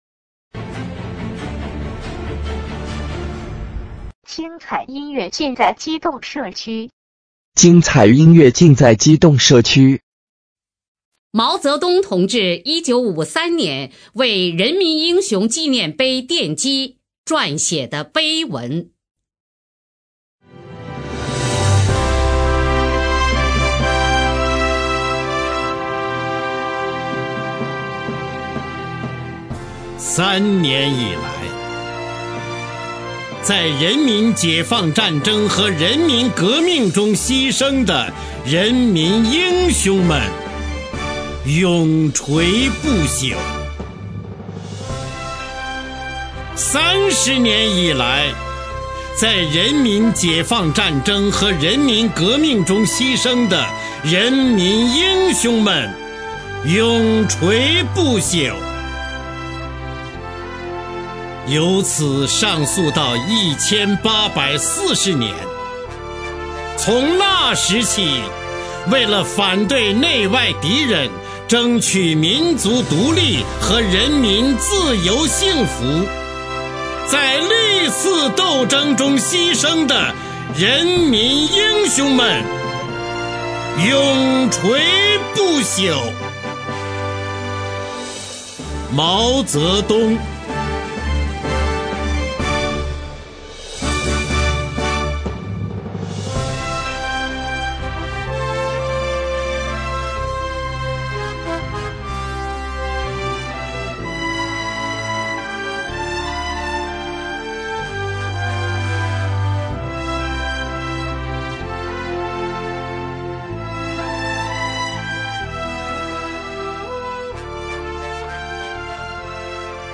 中央人民广播电台播音员朗诵
朗诵 方  明